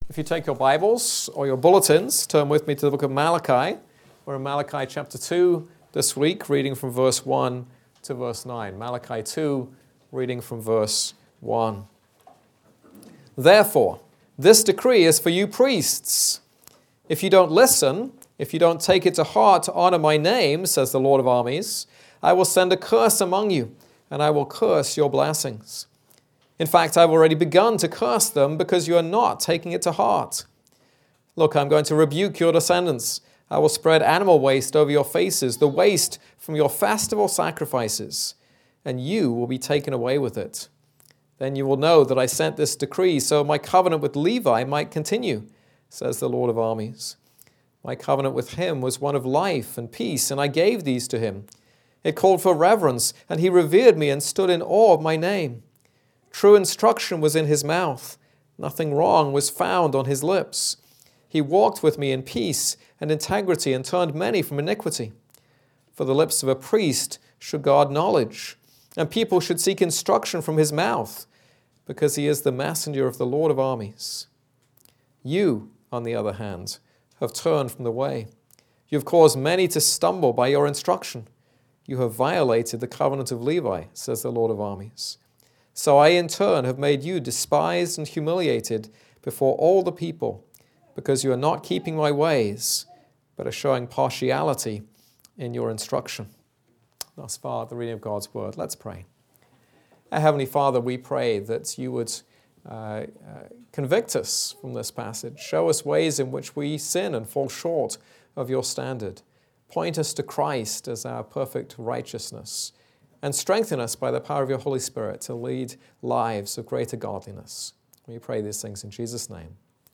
This is a sermon on Malachi 2:1-9.